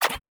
Tab Select 17.wav